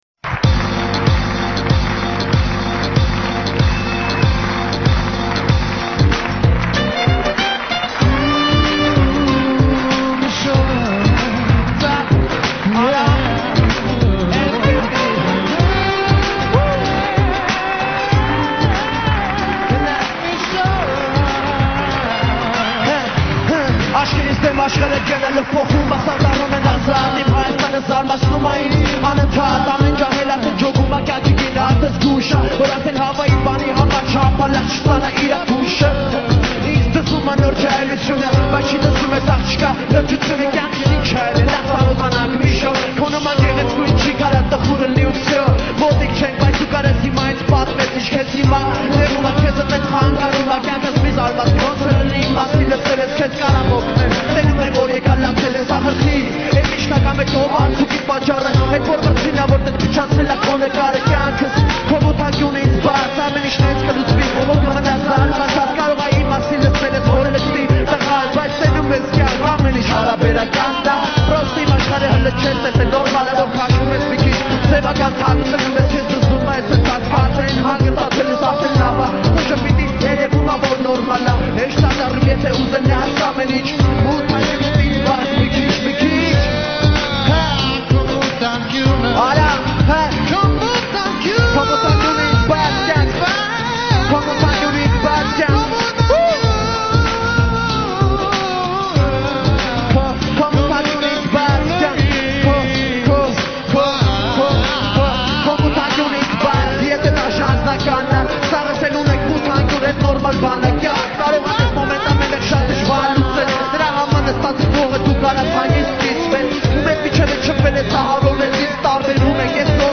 Главная » Файлы » Песни » Армянские